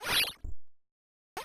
star.wav